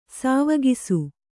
♪ sāvagisu